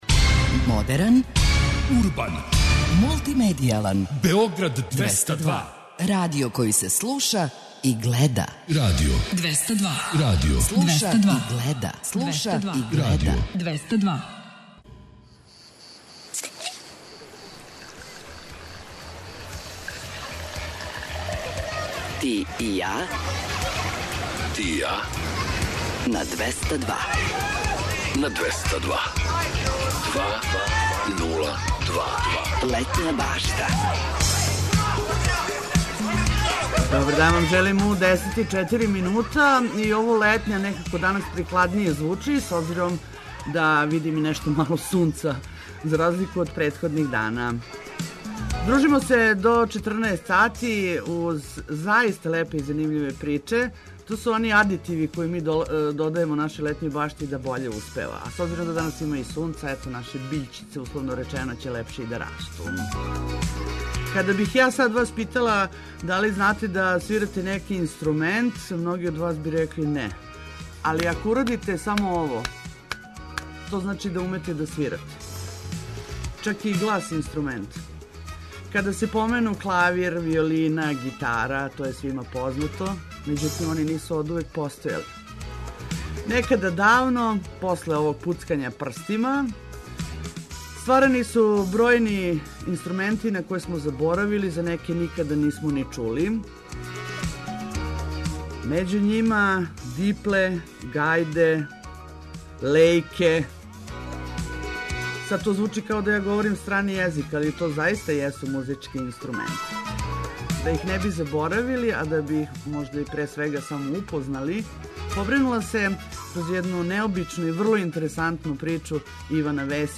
Рикало, дудук, дипле, гајде и лејке су речи које ћете наћи у мало ком речнику, али ћете из инструмената које означавају добити невероватне звуке – и сећања на стара-стара времена у којима су људи налазили забаву баш уз њих.